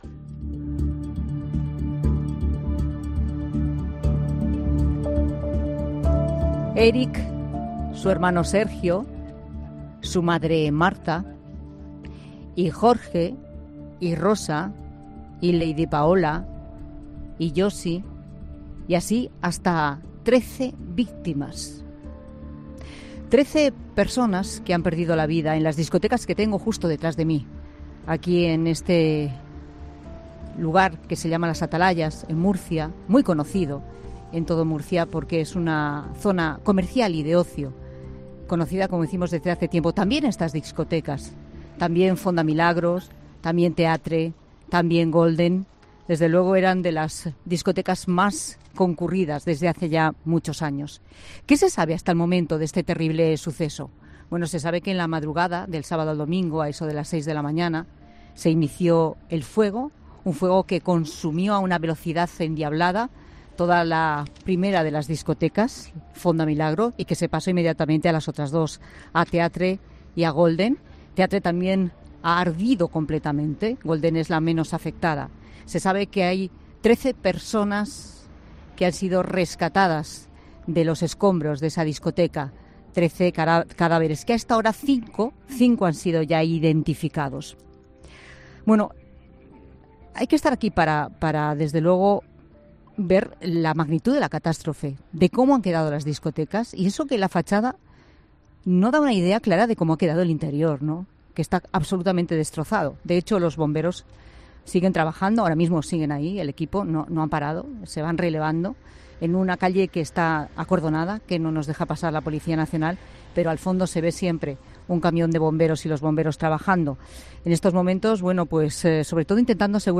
Tragedia y luto en Murcia. En La Tarde nos hemos desplazado hasta Las Atalayas, la zona de las tres discotecas arrasadas por las llamas